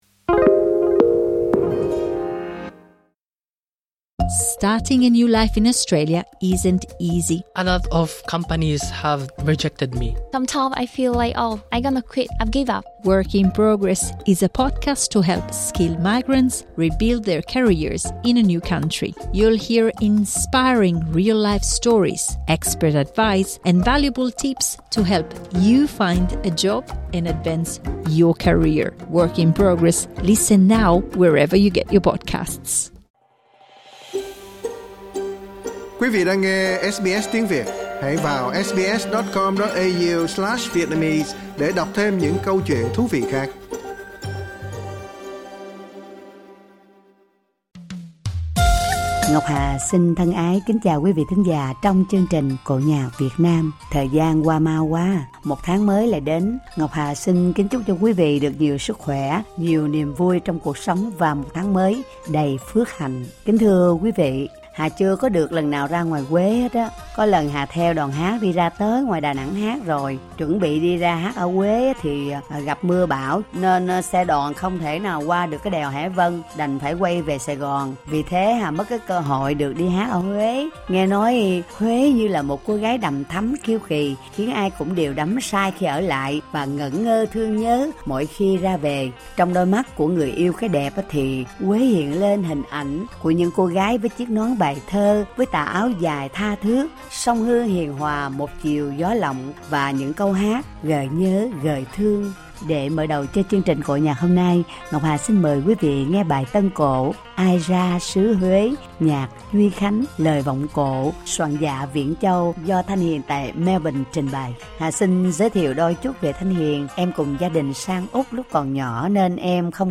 bài tân cổ